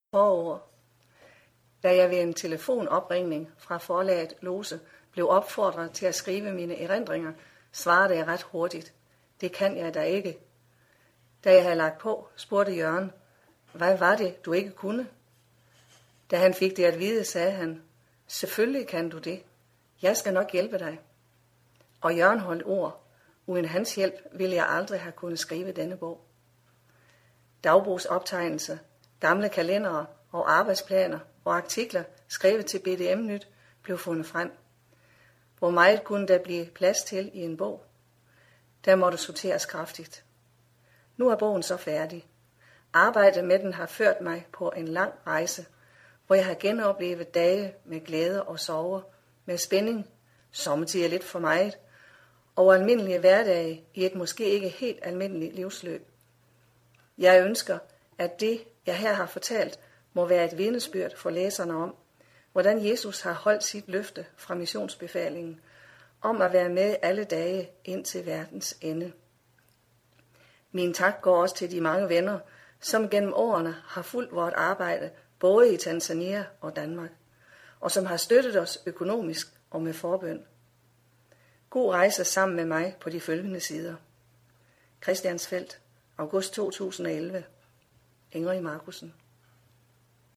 Lydbog